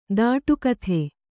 ♪ dāṭu kathe